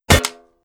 Weapon_Drop 05.wav